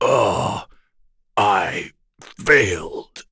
Bernheim-Vox_Dead.wav